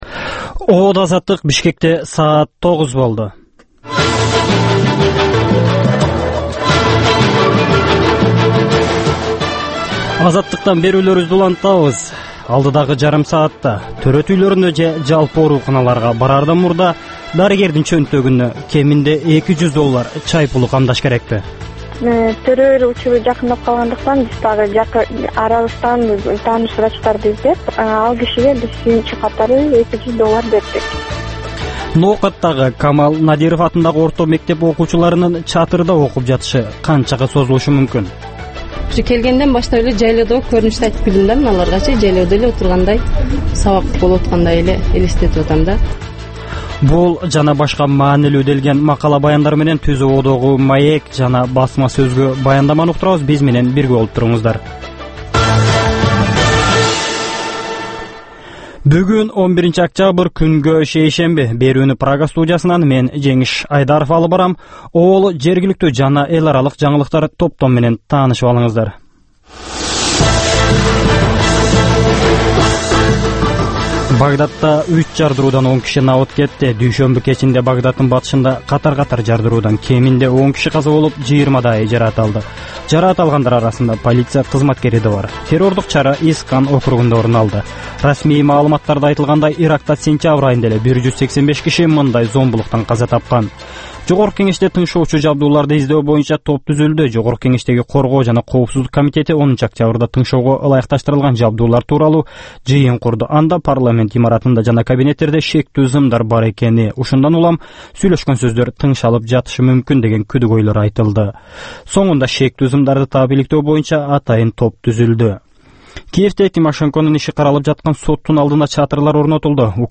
Таңкы 9дагы кабарлар